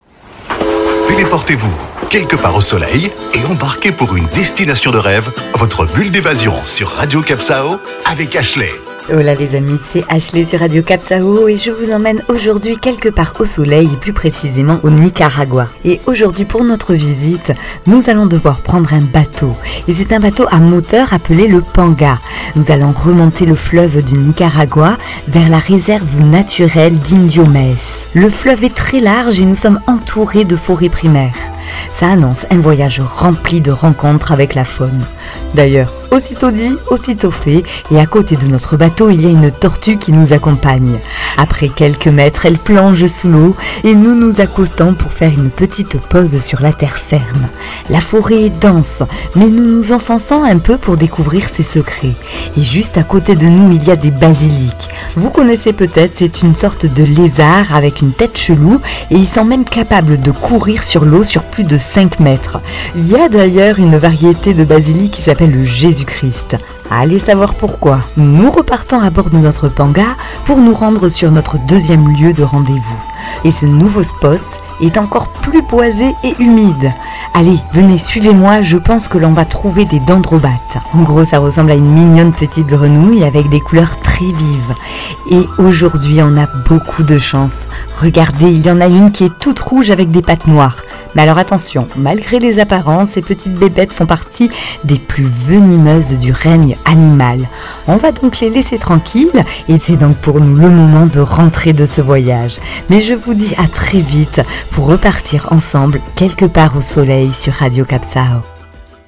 Carte postal sonore : montez à bord d'un panga le long du fleuve Nicaragua et enfoncez-vous dans la forêt primaire humide, où la faune et la flore locale vous réserve des surprises ...